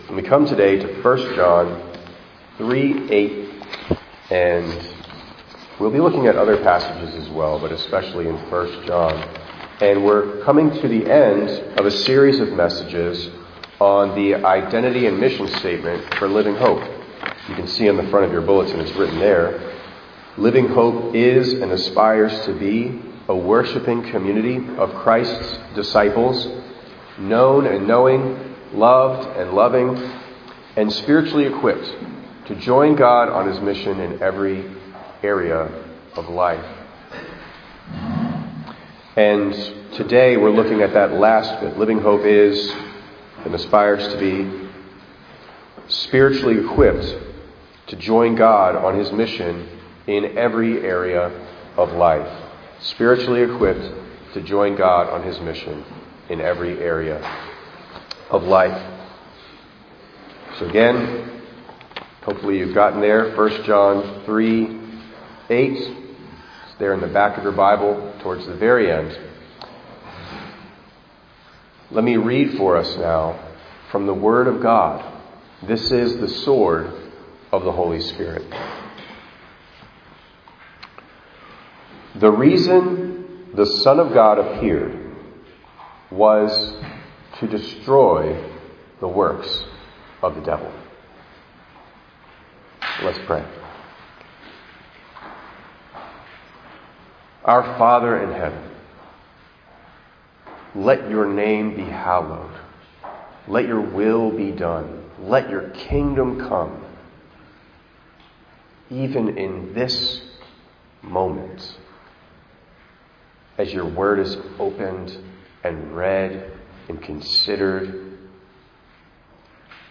3_8_26_ENG_Sermon.mp3